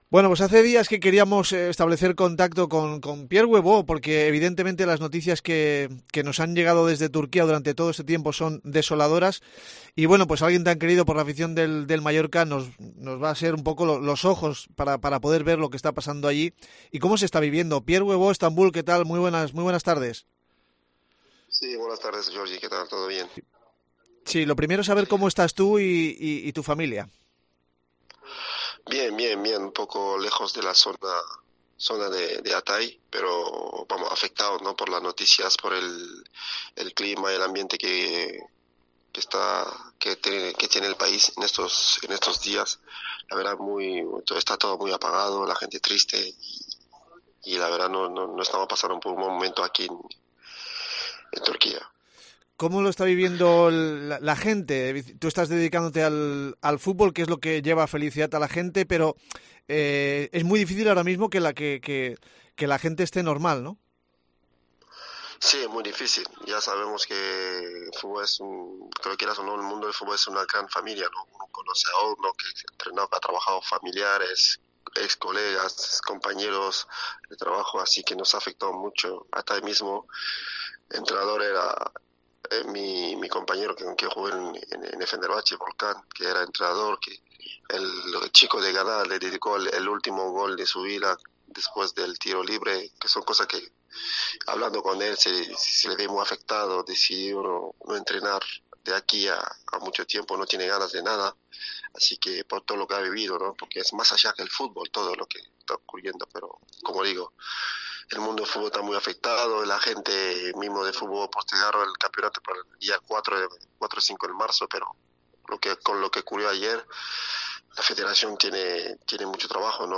Hablamos con el ex delantero del Mallorca, ahora segundo entrenador del Basaksehir de Estambul, sobre cómo está viviendo la situación en Turquía tras el desolador terremoto